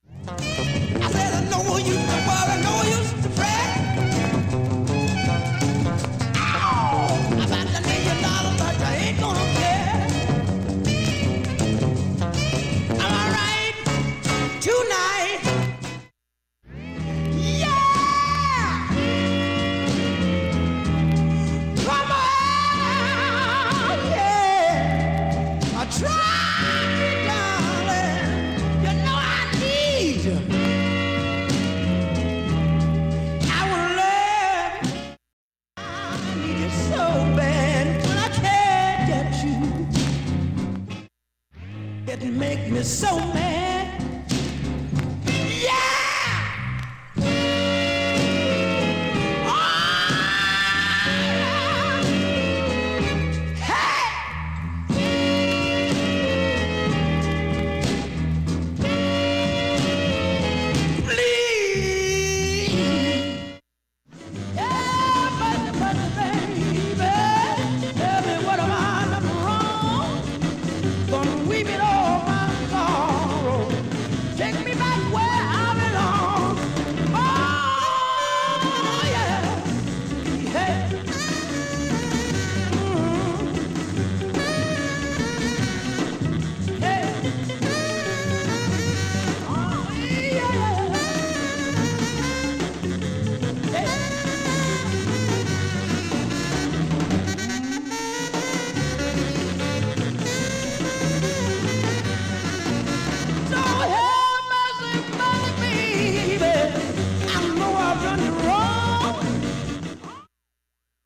音質良好全曲試聴済み。
音質目安にどうぞ
３回までのかすかなプツが４箇所
単発のかすかなプツが７箇所
◆ＵＳＡ盤オリジナル Mono